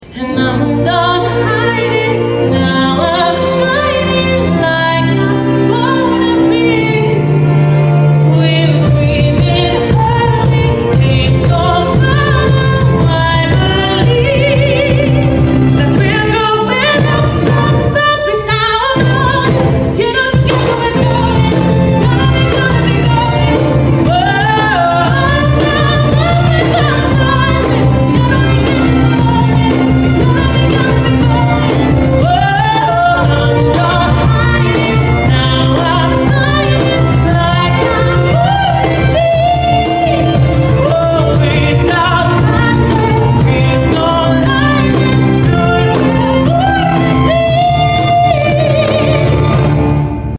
strings version 🎻✨ A special unplugged version